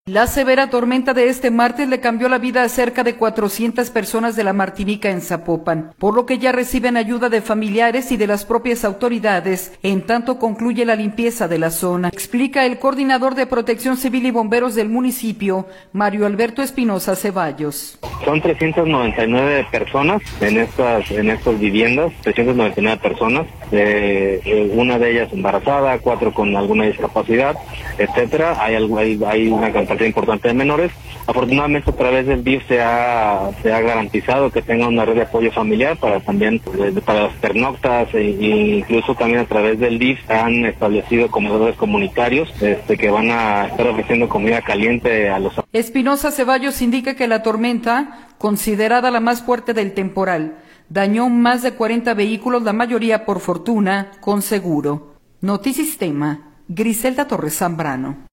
La severa tormenta de este martes le cambió la vida a cerca de 400 personas de la Martinica en Zapopan, por lo que ya reciben ayuda de familiares y de las propias autoridades, en tanto concluye la limpieza de la zona, explica el coordinador de Protección Civil y Bomberos del municipio, Mario Alberto Espinosa Ceballos.